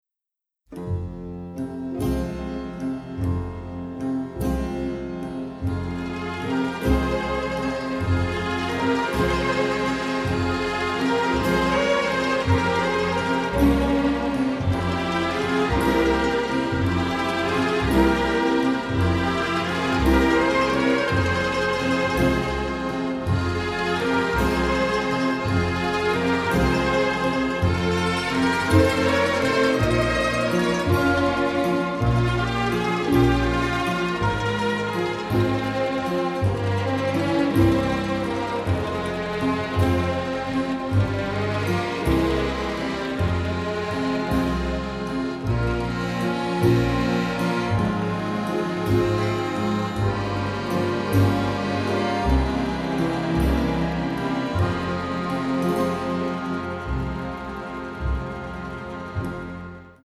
Additional Music (mono)